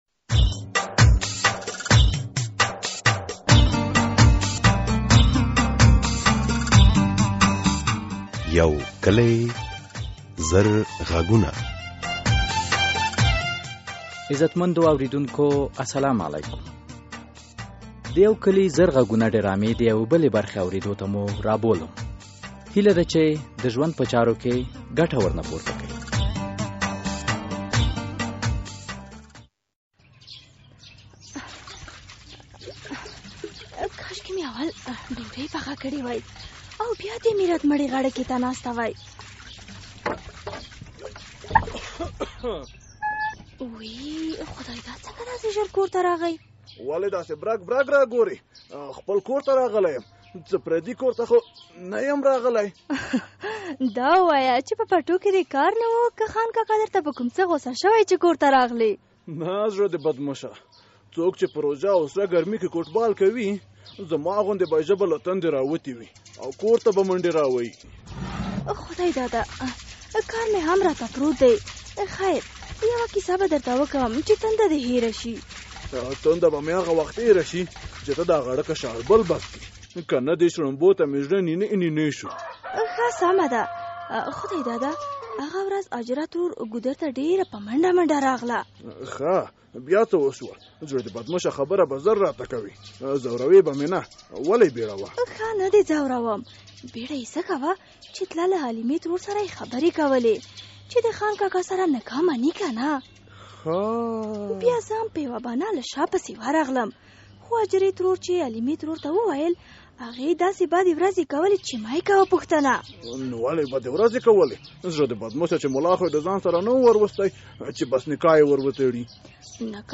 یوکلي او زرغږونه ډرامه هره اونۍ د دوشنبې په ورځ څلور نیمې بجې له ازادي راډیو خپریږي.